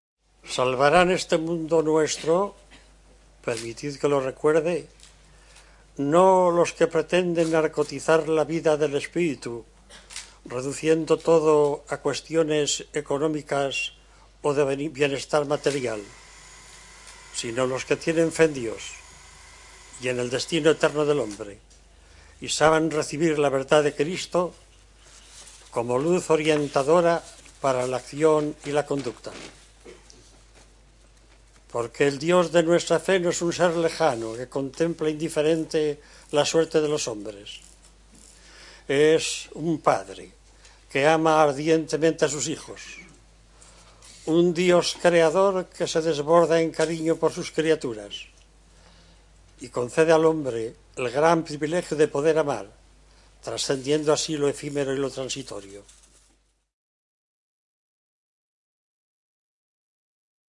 Fe (57”) Universidad de Navarra (España)